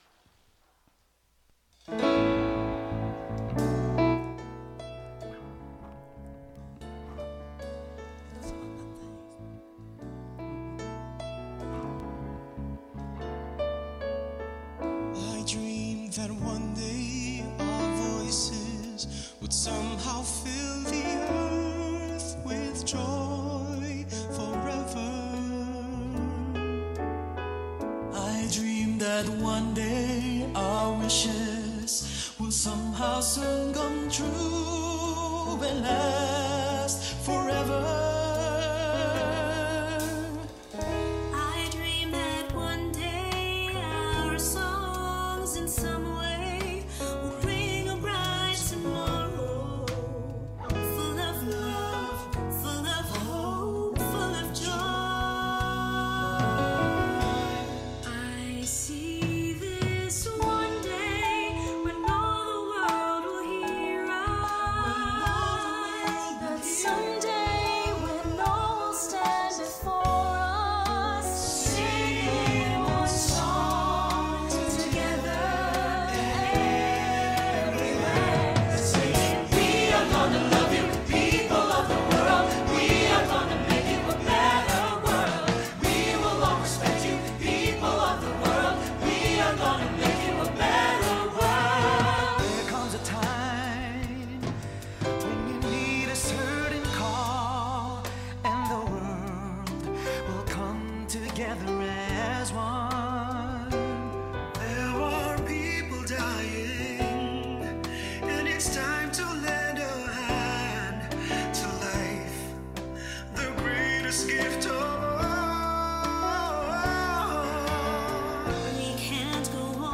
Spiritual Leader Series: Sermons 2023 Date